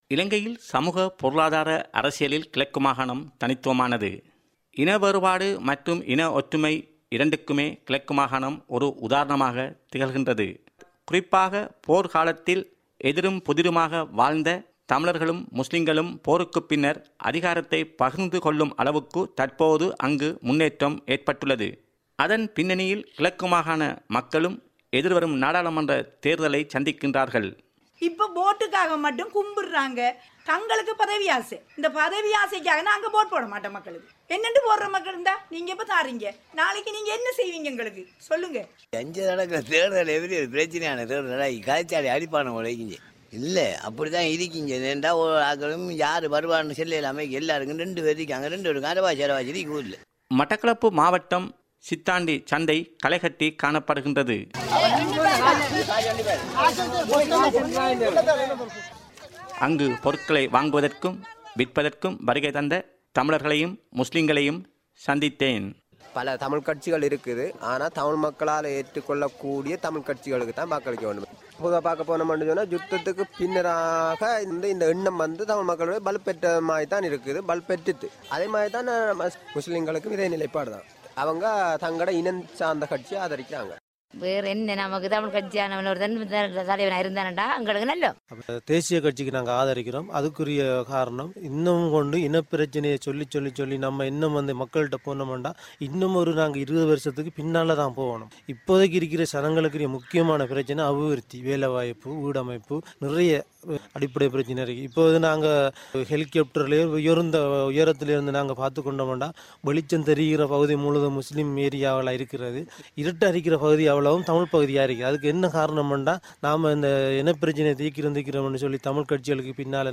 அங்குள்ள தேர்தல் களத்தில் மக்களின் கருத்துக்களை பிரதிபலிக்கும் ஒலிப்பெட்டகம்.